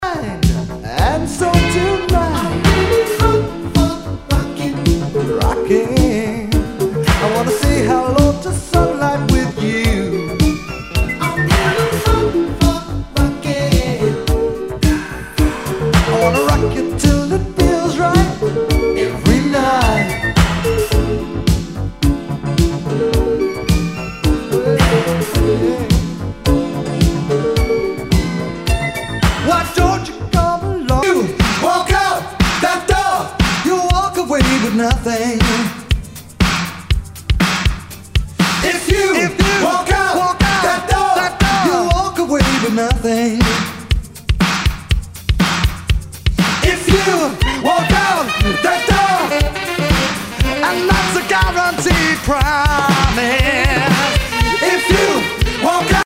SOUL/FUNK/DISCO
ナイス！シンセ・ポップ・ディスコ！